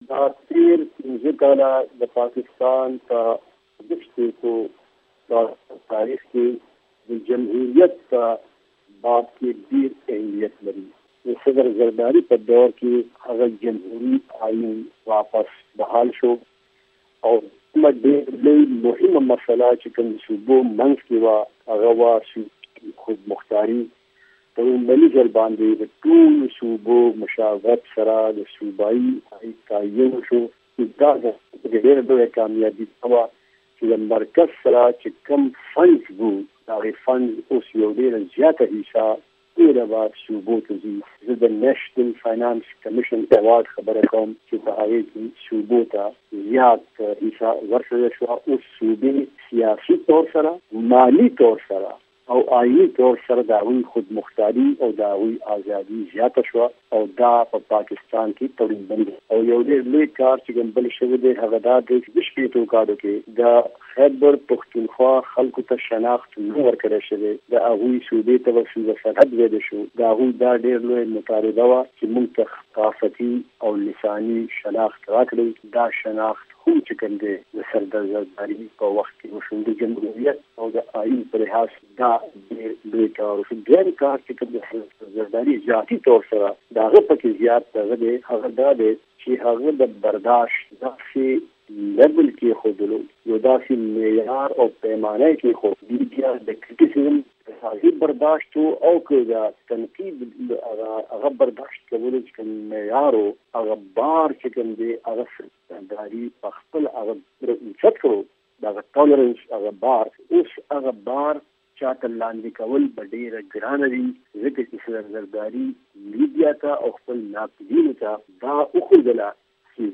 سنیټر فرحت الله بابر مرکه